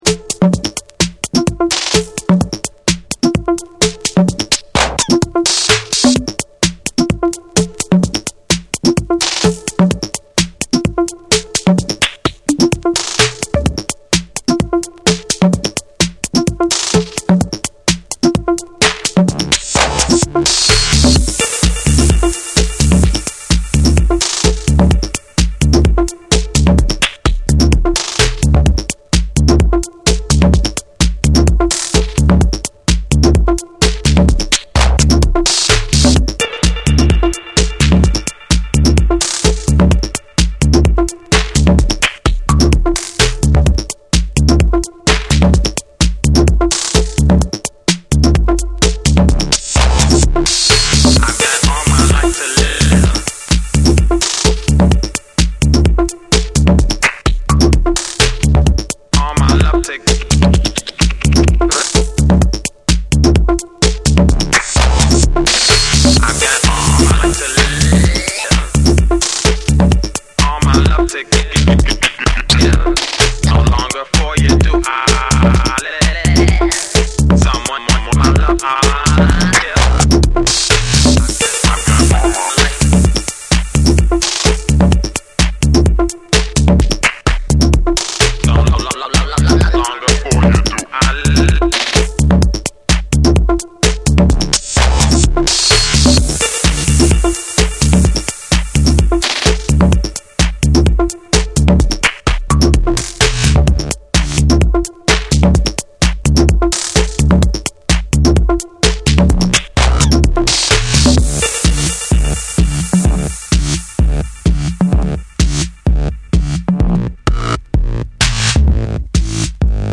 ミニマルでグルーヴィーな低域と独特のテンション感がクセになる